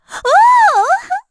Erze-Vox_Happy4_kr.wav